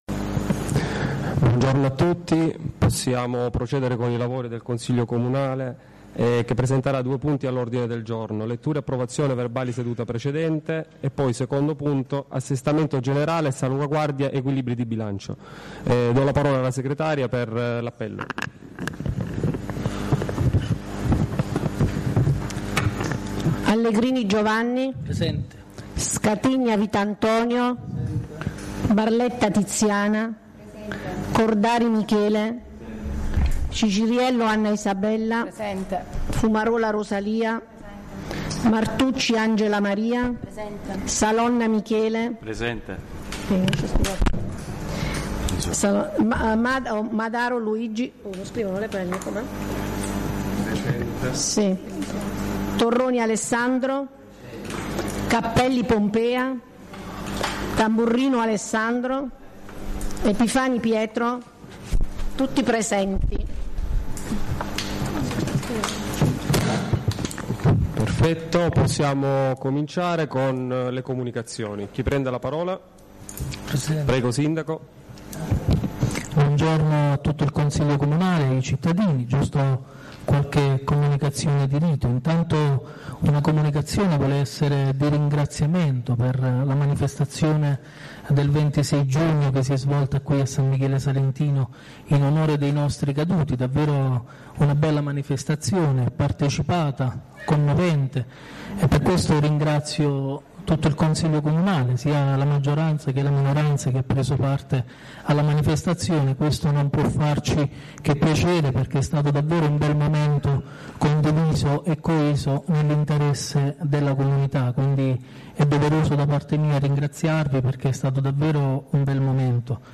La registrazione audio del Consiglio Comunale di San Michele Salentino del 30/07/2019